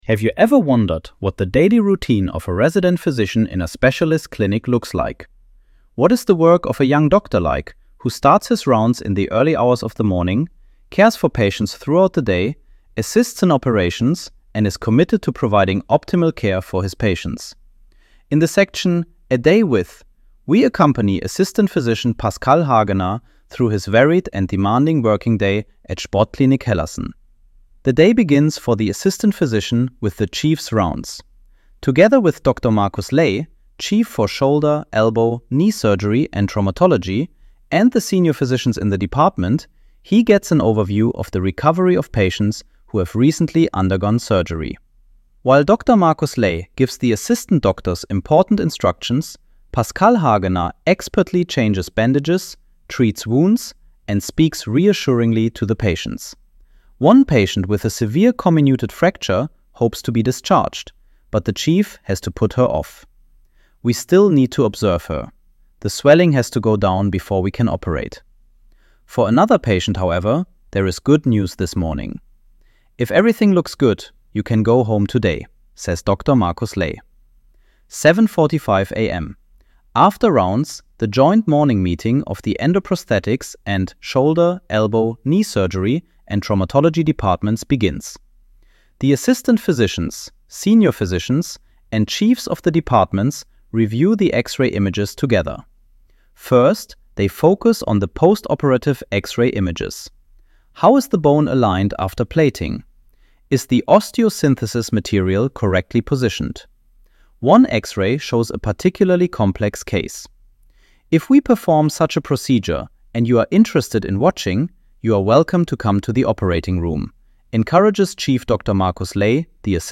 Have article read aloud ▶ Play audio Have you ever wondered what the daily routine of a resident physician in a specialist clinic looks like?